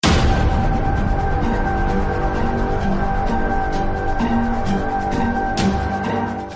Sml Intense Sound